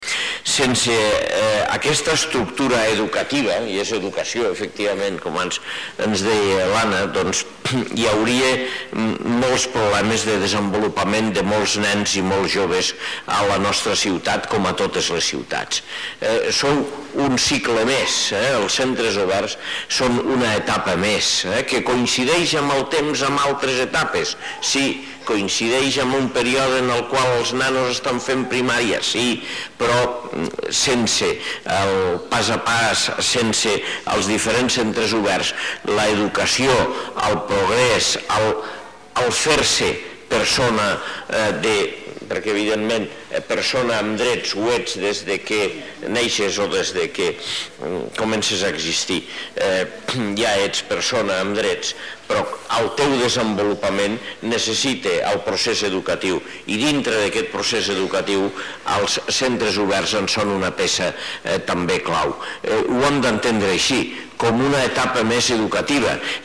tall-de-veu-de-lalcalde-angel-ros-sobre-els-10-anys-del-centre-obert-municipal-pas-a-pas